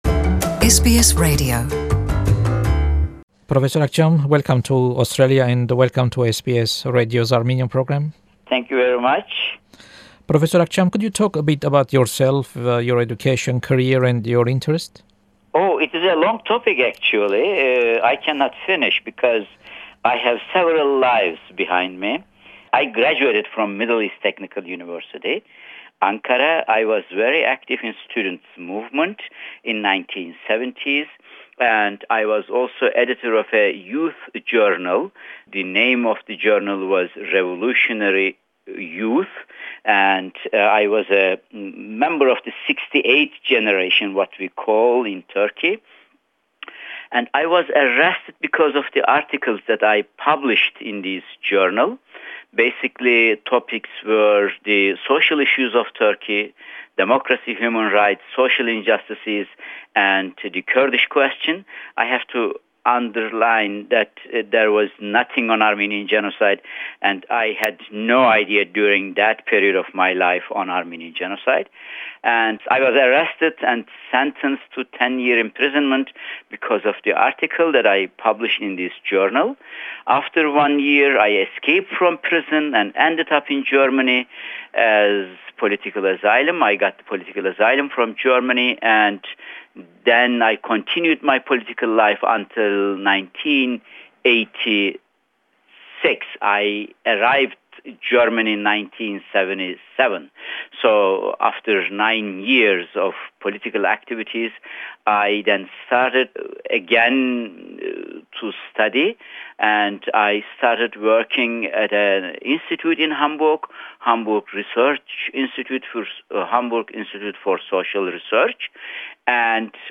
An interview with historian Taner Akcam during his visit to Australia in 2012.